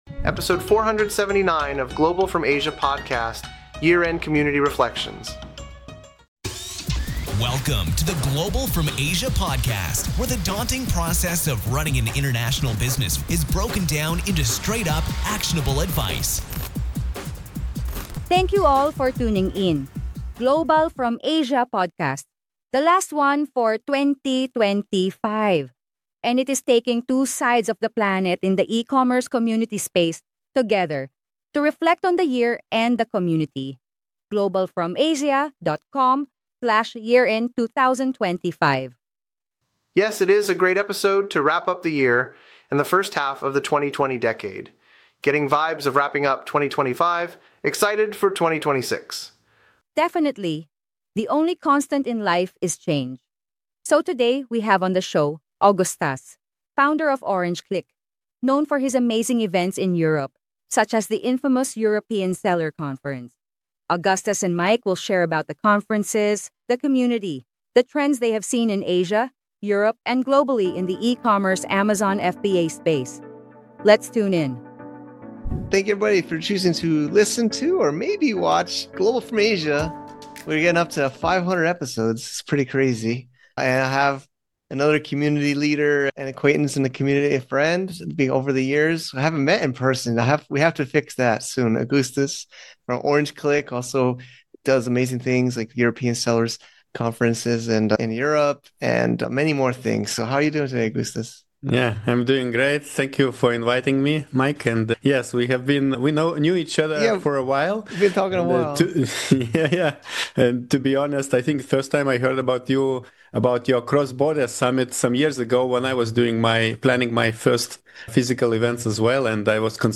A conversation about patience, fearlessness, collaboration, and why the quiet stretches matter just as much as the work.